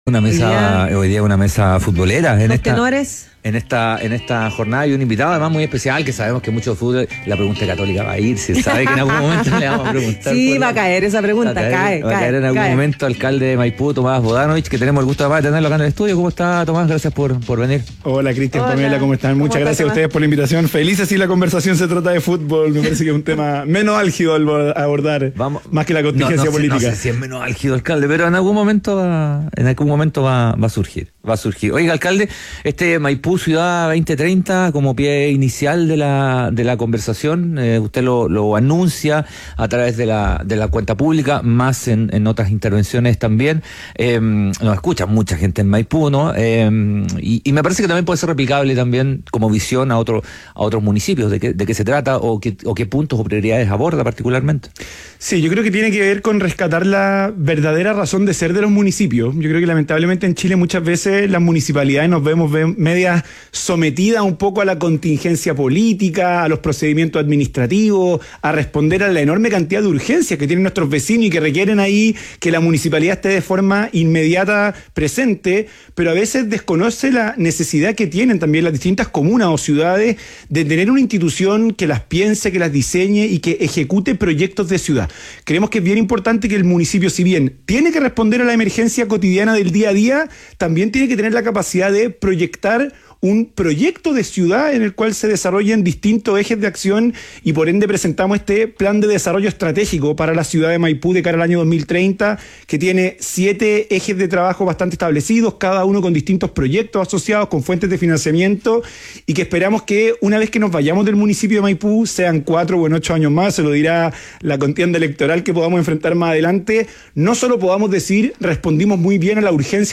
El líder municipal de Maipú habló de manera distendida en “Ciudadano ADN” acerca de su lado más romántico.
Entrevista a Tomás Vodanovic, alcalde de Maipú - Ciudadano ADN